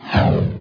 whosh_1.mp3